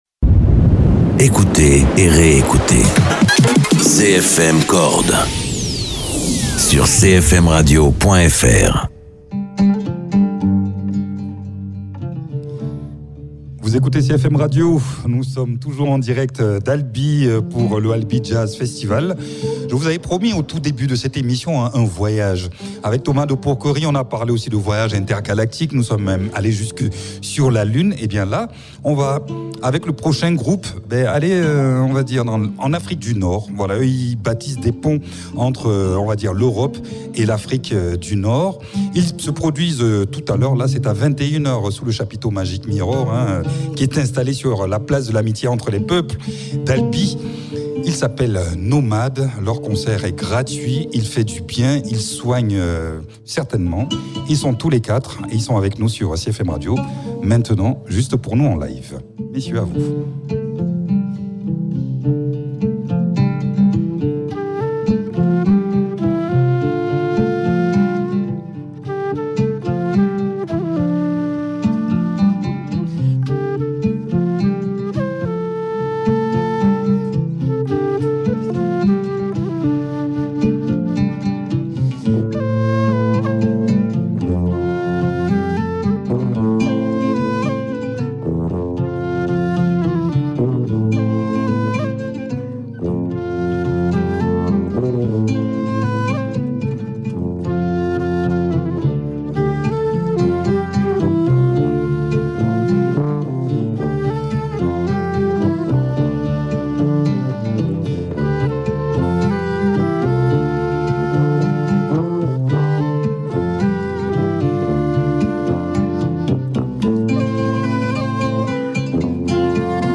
flûte
saxophone alto
tuba
Oud.